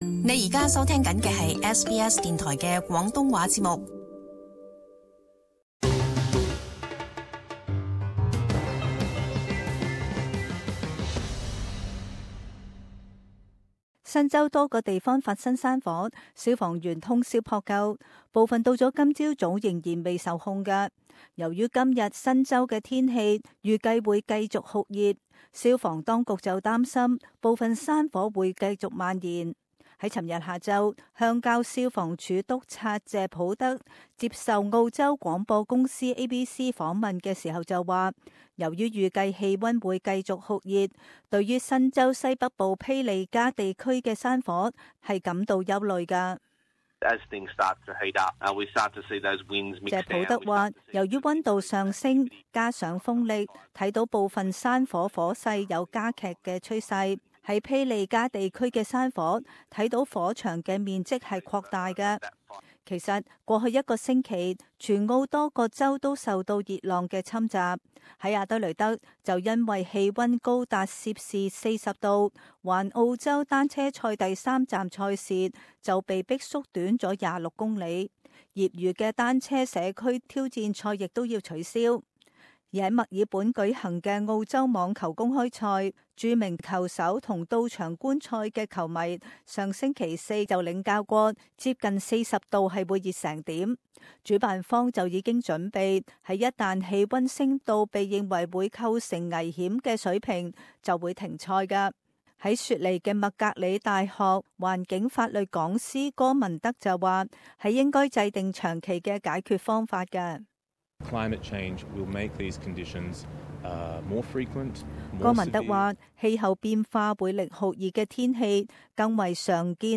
【時事報導】熱浪危機